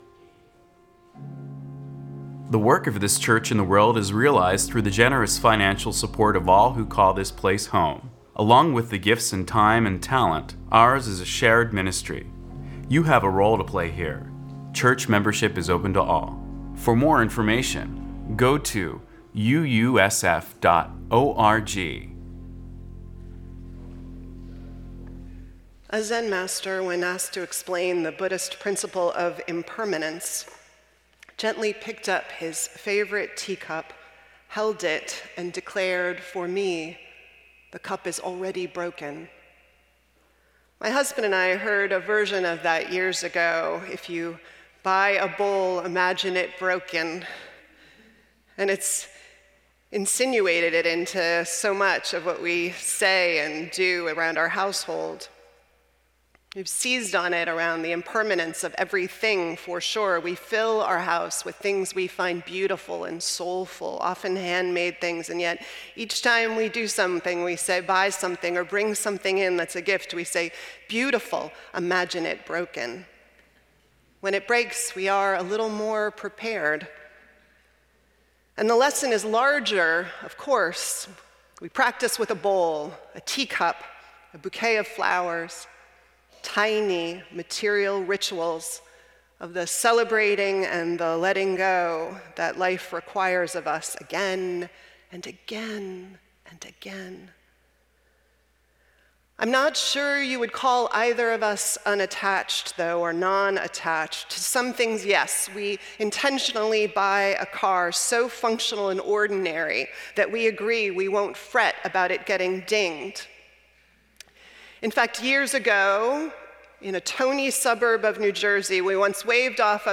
Our musicians this Sunday have a ministry companioning those through death and dying.